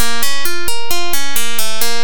I liked the Harpsicord sound, so I thought something a little minor would be welcome. Short Riff on the B-flat Minor Arpeggio
Channels: 2 (stereo)